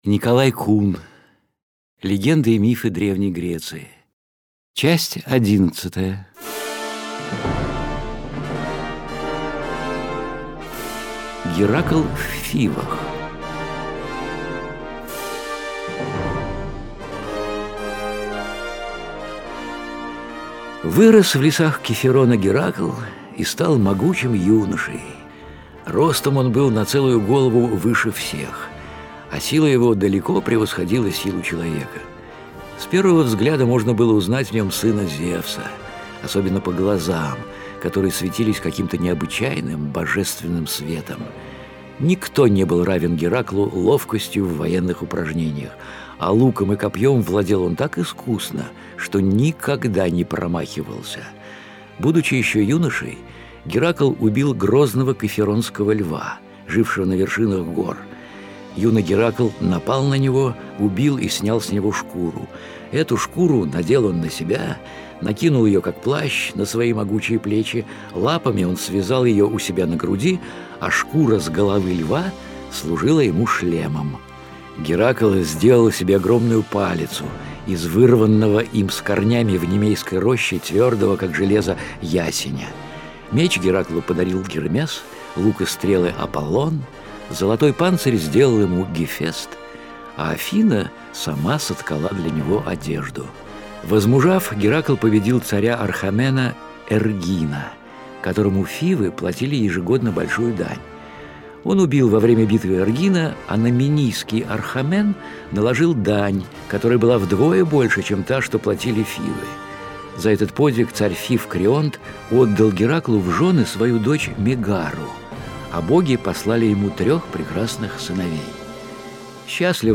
Литературные чтения (20:45)